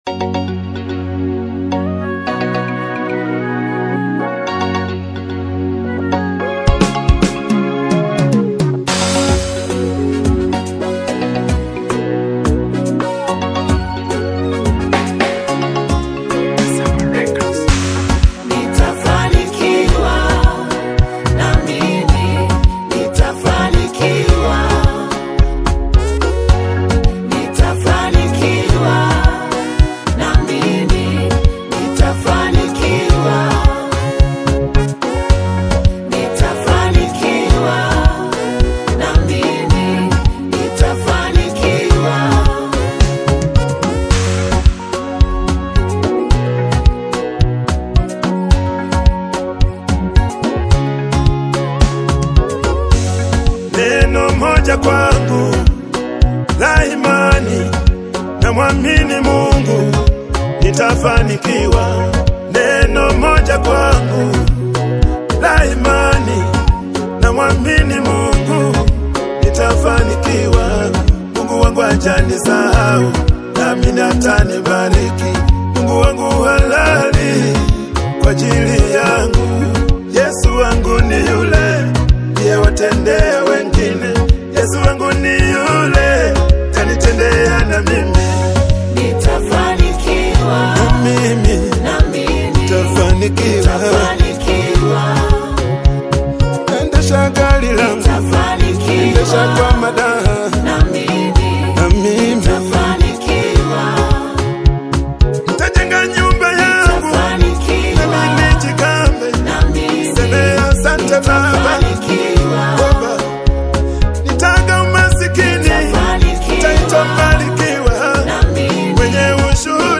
The soothing and deeply encouraging single
gentle, swaying rhythm